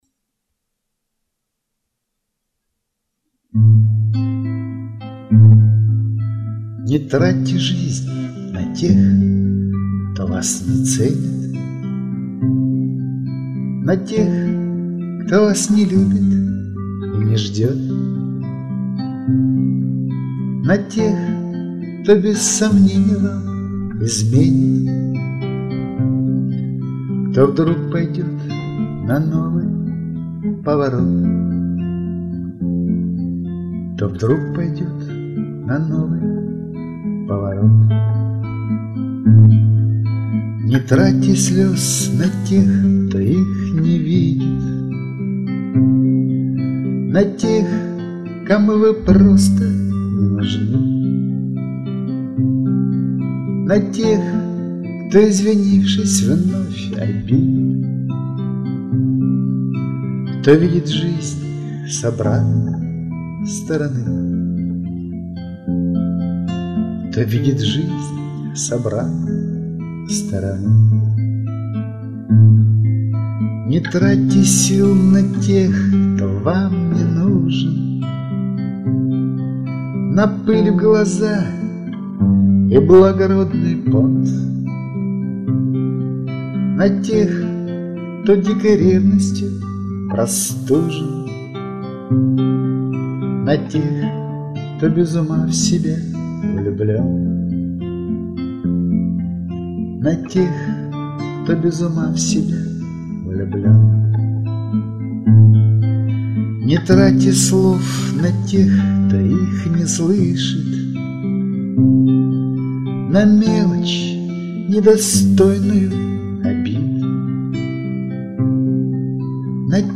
"Не тратьте" ("Совет" в форме песни)